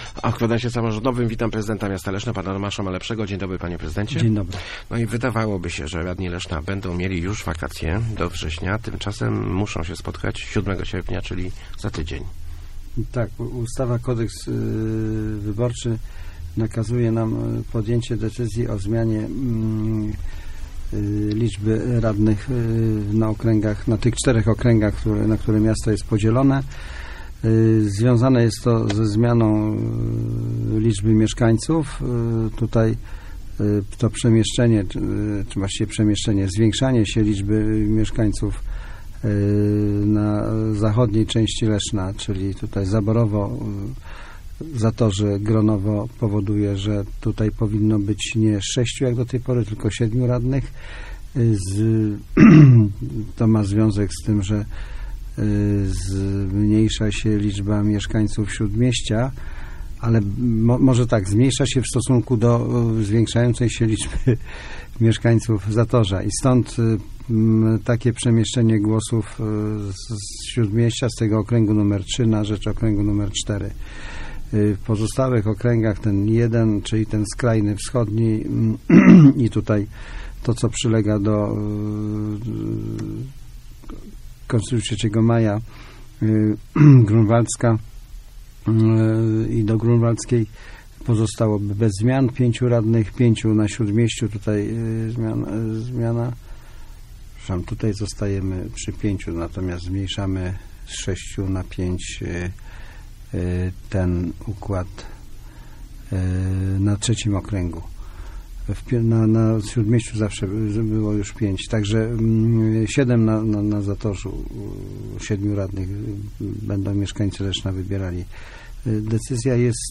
Gościem Kwadransa był prezydent Leszna Tomasz Malepszy.